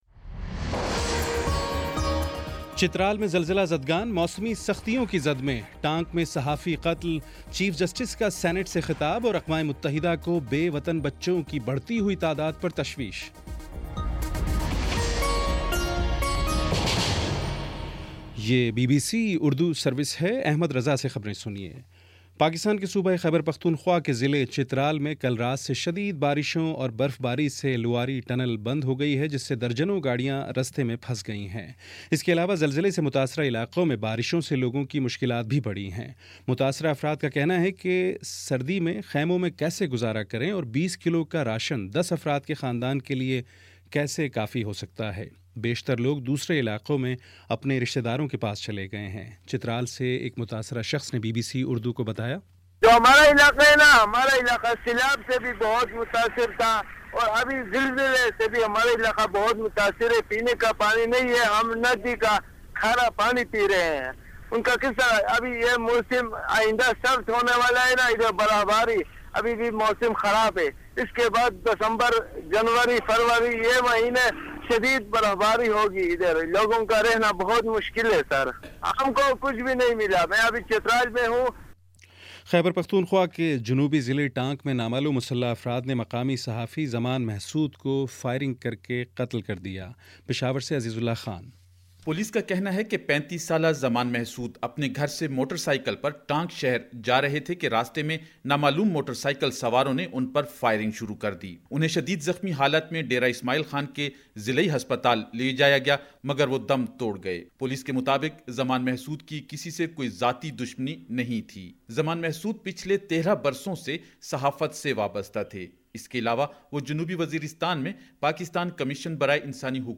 نومبر 03 : شام پانچ بجے کا نیوز بُلیٹن